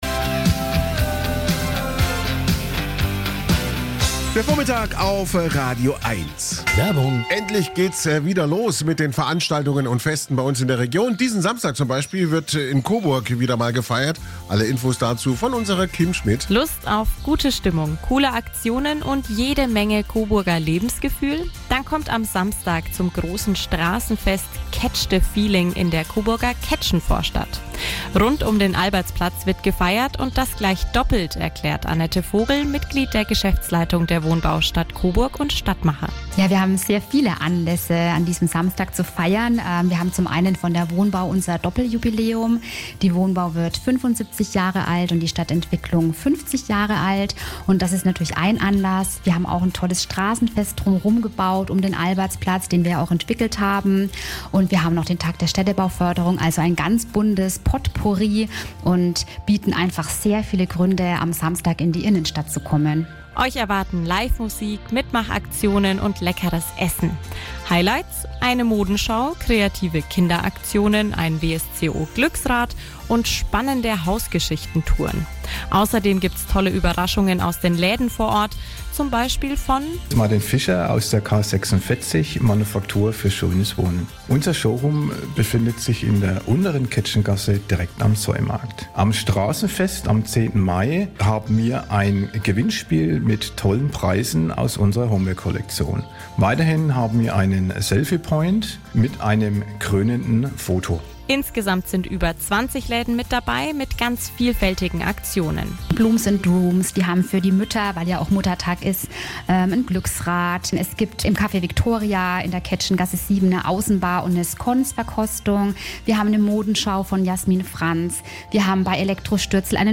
Radio-Spot